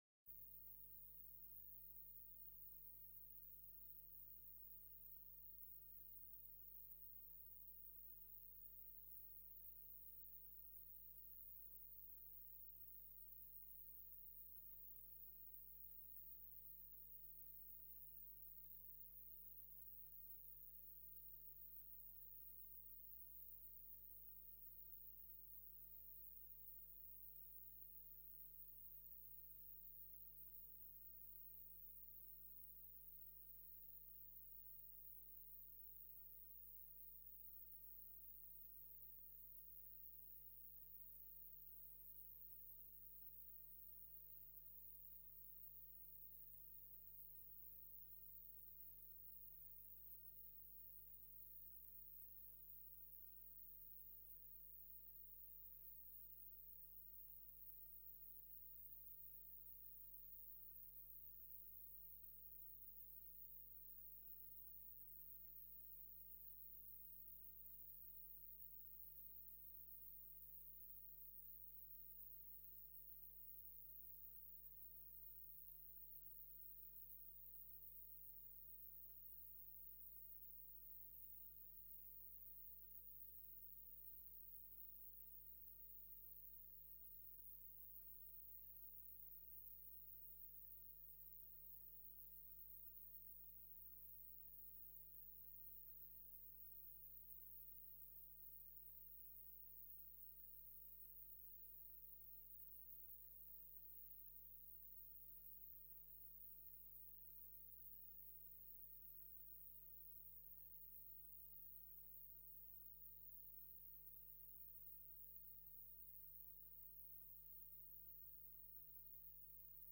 Commissie Bestuur, Financien en Economische zaken 06 februari 2017 19:30:00, Gemeente Noordoostpolder
Download de volledige audio van deze vergadering